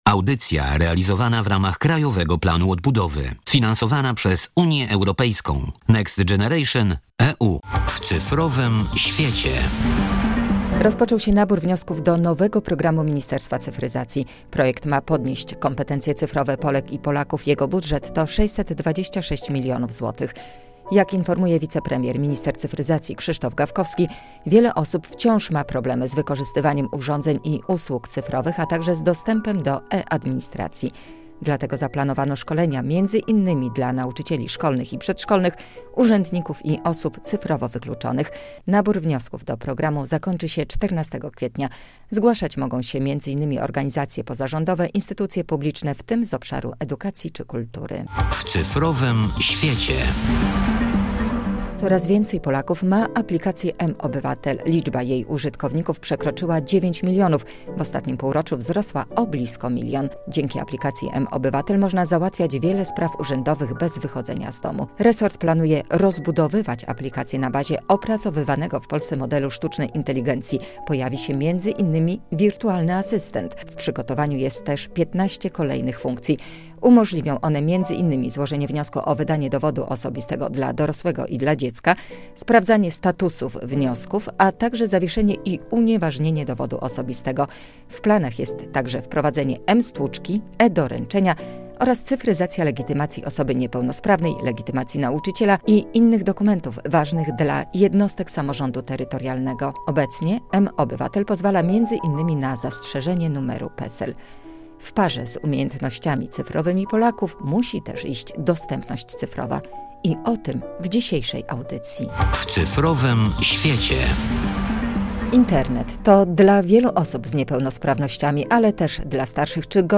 Dostępność cyfrowa - audycja